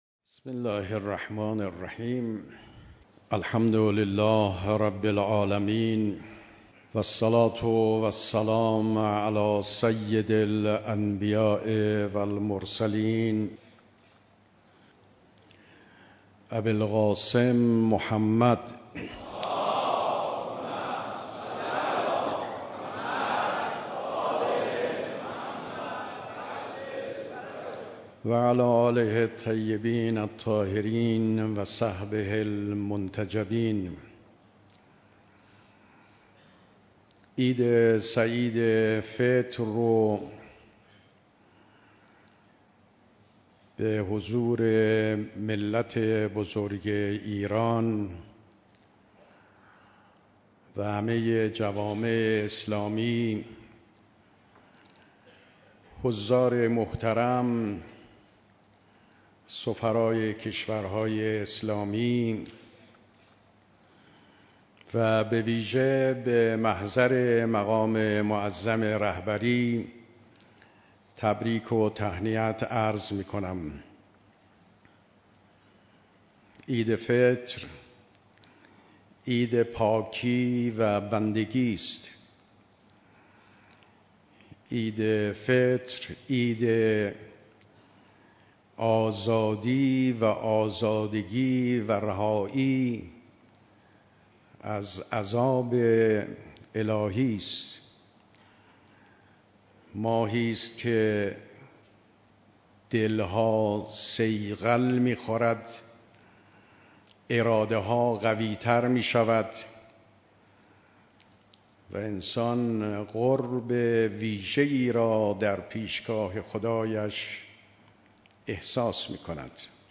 دیدار مسئولان، سفرای کشورهای اسلامی و جمعی از اقشار مختلف مردم
سخنرانی ریاست محترم جمهور جناب آقای روحانی